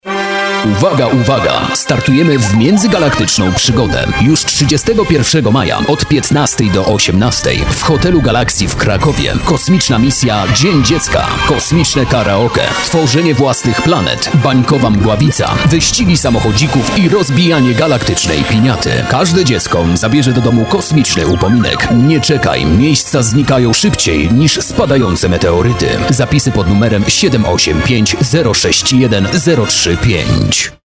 GalaktycznyDzienDziecka-mix-A-spot-radio-Famka.mp3